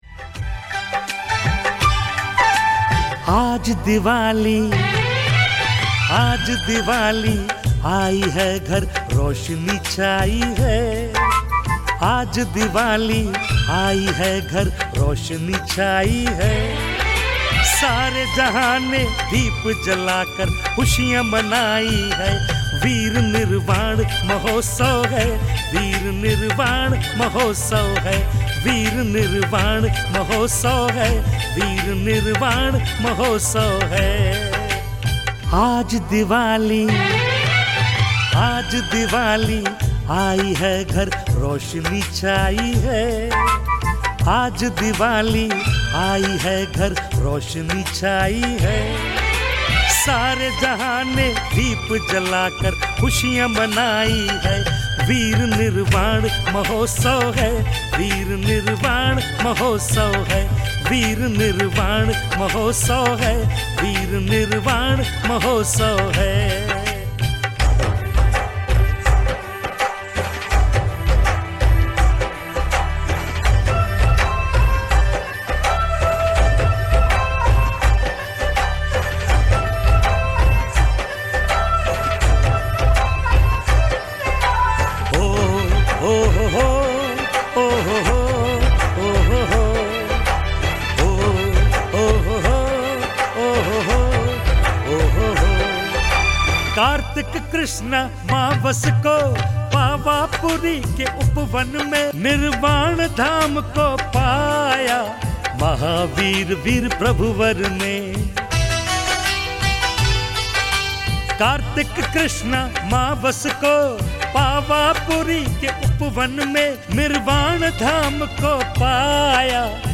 दीवाली भजन